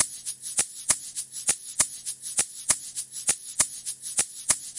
震动器2
描述：100bpm循环蛋振打击乐器